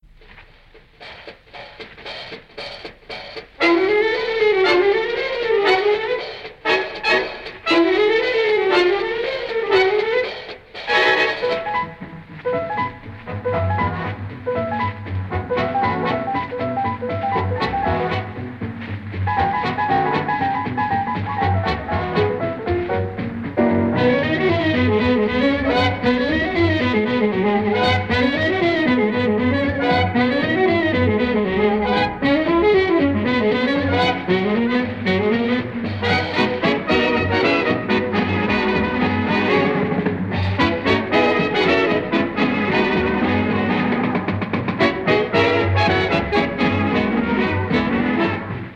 saxes,cl